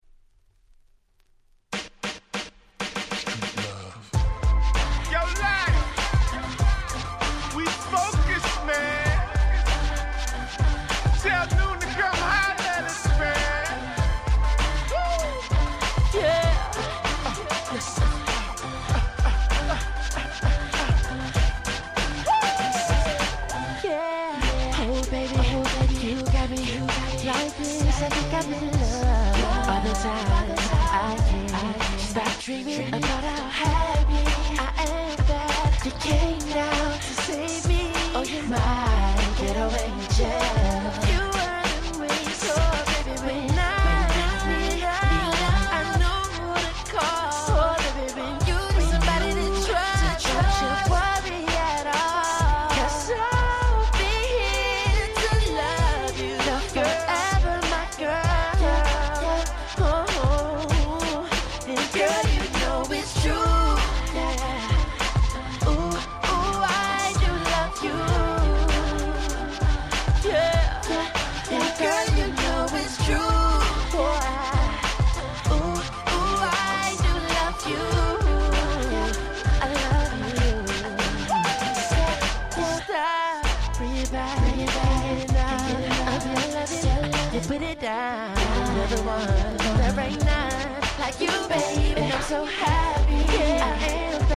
10' Super Hit R&B !!